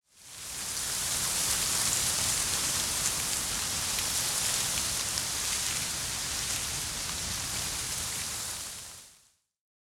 windtree_1.ogg